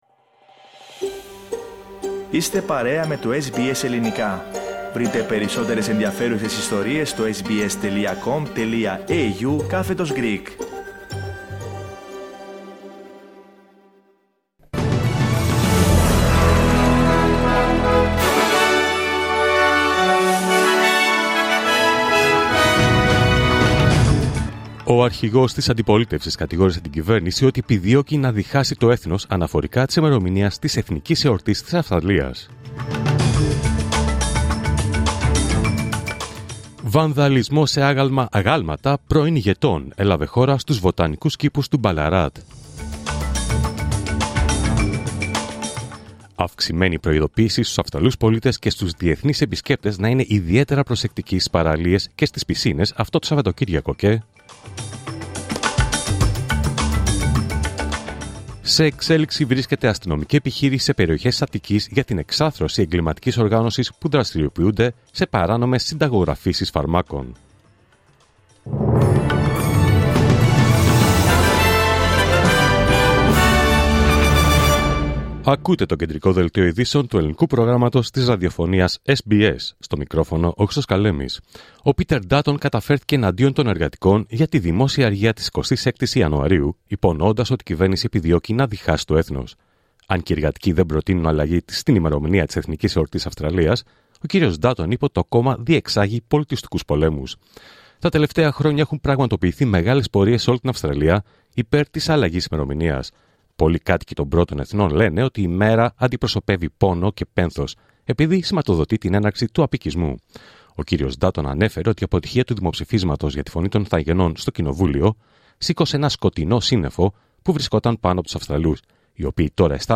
Δελτίο Ειδήσεων Παρασκευή 24 Ιανουαρίου 2025